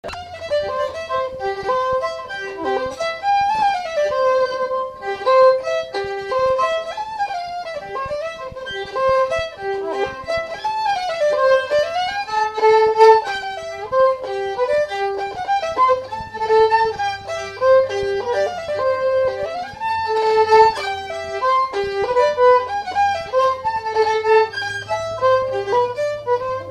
Séga
Instrumental
danse : séga
Pièce musicale inédite